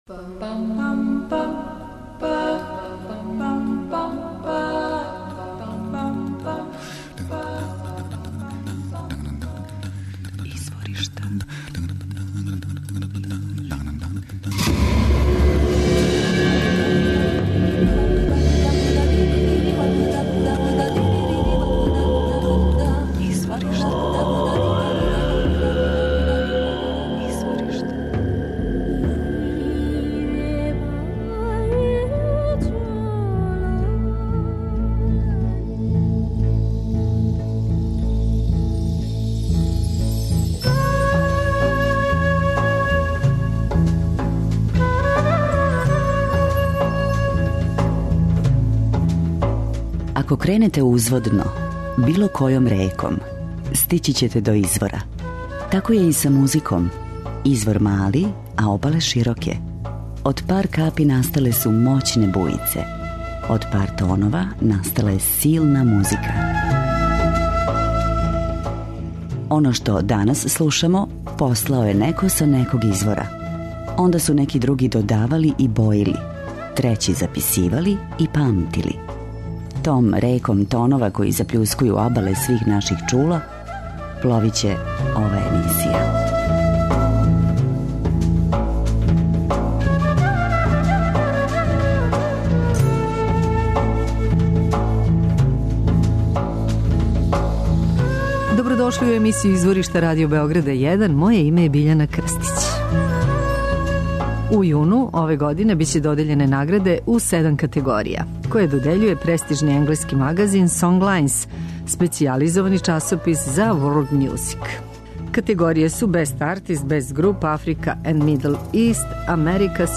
Микс виолине, гитаре, хармонике, хурдy-гурдy.